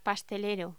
Locución: Pastelero
voz